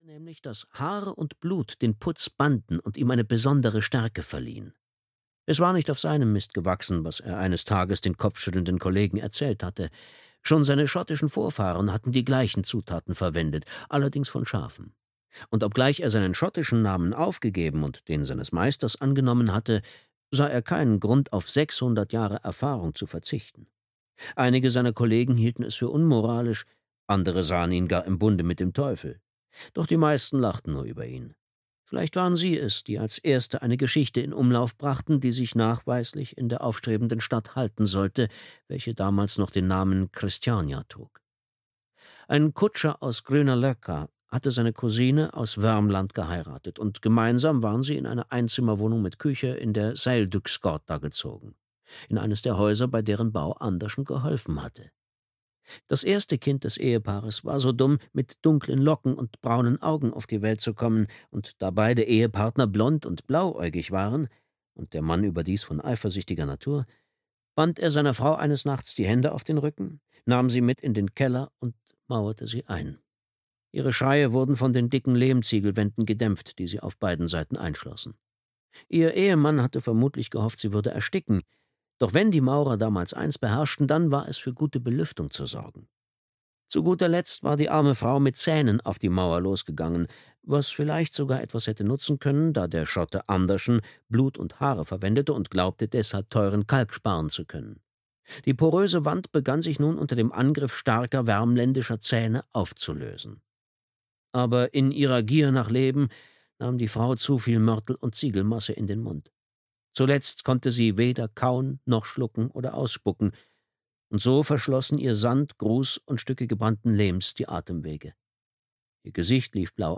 Das fünfte Zeichen (DE) audiokniha
Ukázka z knihy